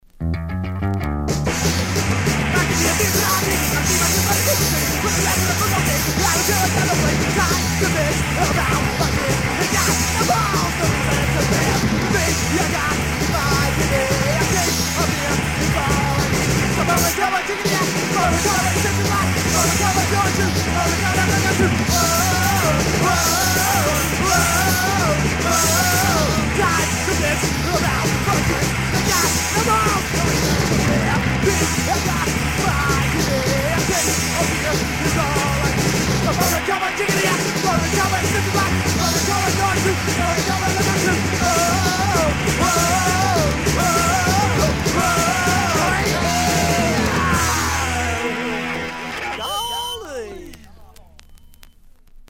These tunes blaze!